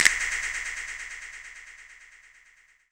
LP Click.wav